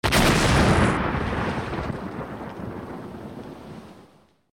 otherdestroyed3.ogg